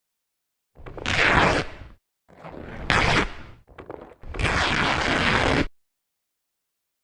Tearing Denim
Tearing Denim is a free sfx sound effect available for download in MP3 format.
yt_NON1-VtyYzo_tearing_denim.mp3